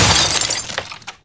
Glass shattering
Glass molecular structure failing and rearranging into small pieces.
s_glass_breaking-_ghb_45.wav